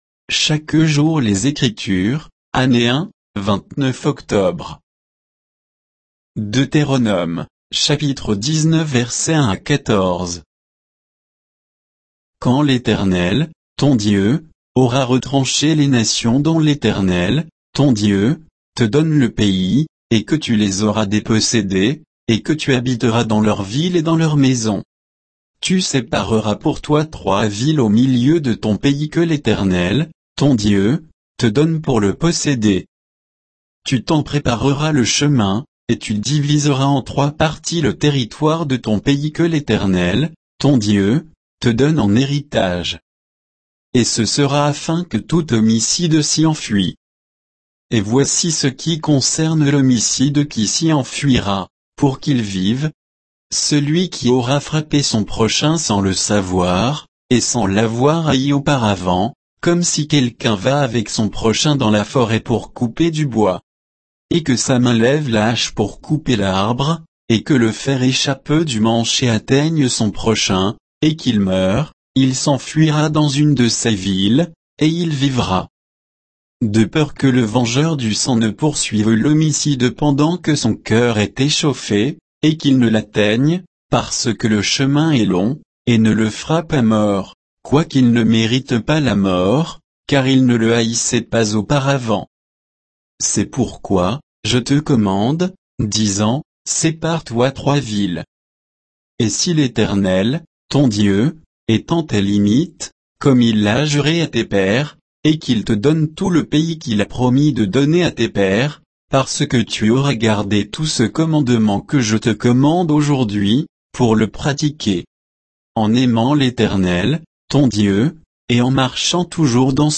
Méditation quoditienne de Chaque jour les Écritures sur Deutéronome 19, 1 à 14